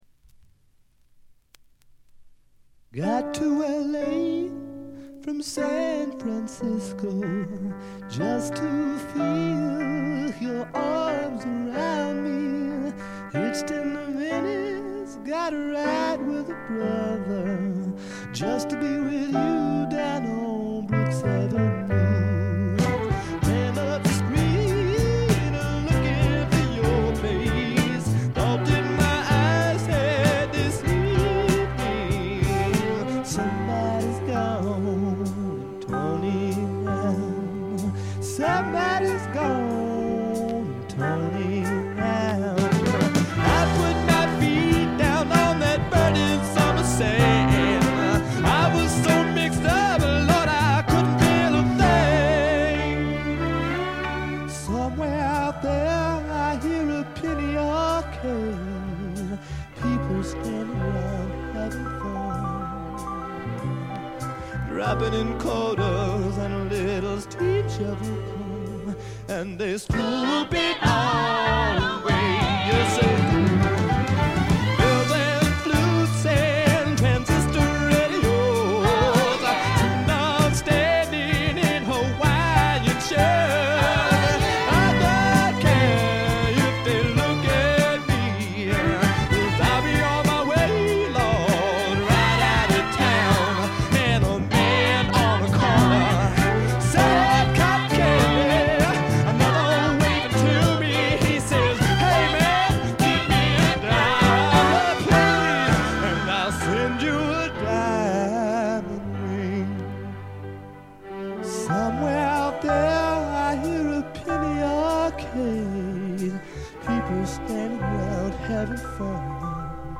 ロックとソウル境界線を行き来する傑作です。
試聴曲は現品からの取り込み音源です。